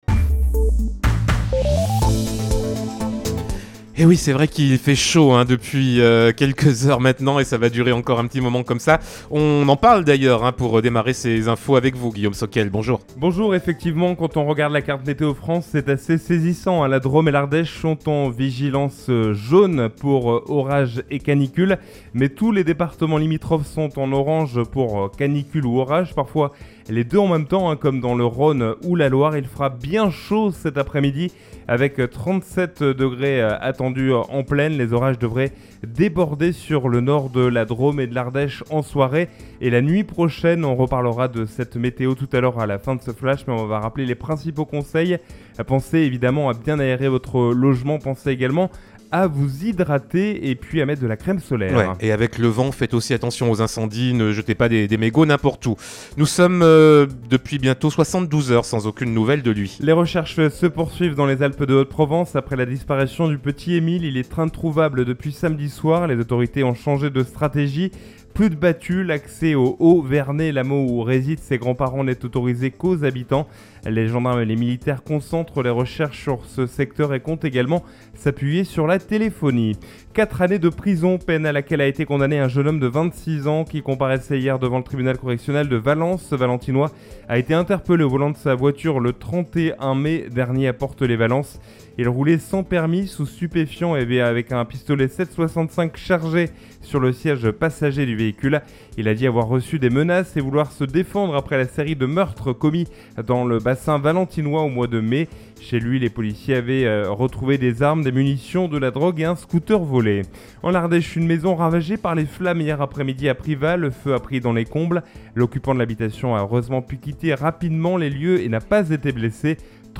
Mardi 11 juillet : Le journal de 12h